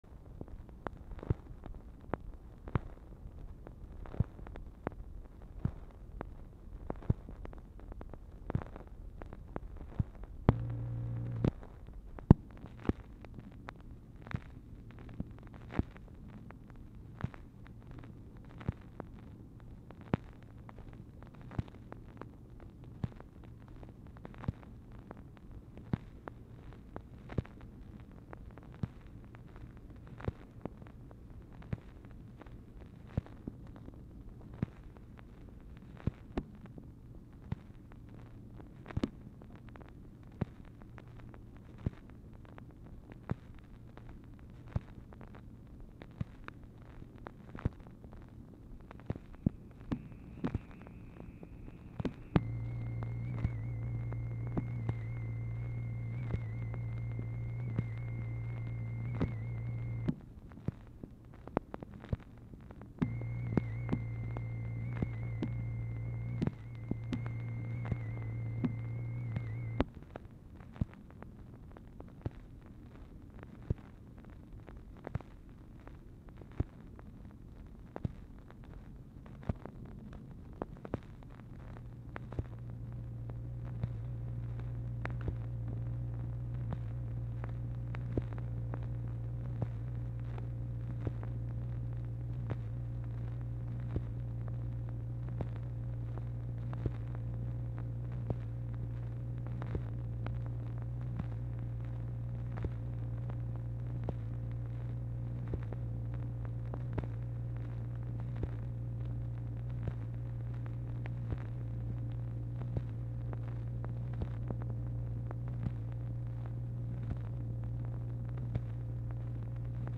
Telephone conversation # 2723, sound recording, MACHINE NOISE, 3/31/1964, time unknown | Discover LBJ
Format Dictation belt
Specific Item Type Telephone conversation